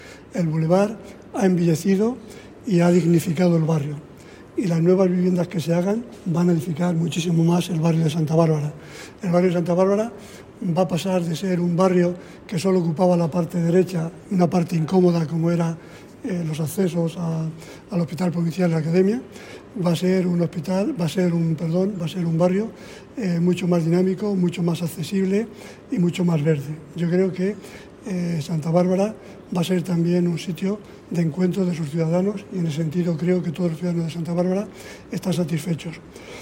Así se lo ha manifestado tras la reunión de la Comisión de Urbanismo, en la que se han aprobado de manera definitiva las bases del programa de actuación urbanizadora de la UA2 de la Unidad Ferroviaria del barrio de Santa Bárbara, una iniciativa anunciada por la alcaldesa en el pasado Debate sobre el Estado de la Ciudad.
Audios José Pablo Sabrido: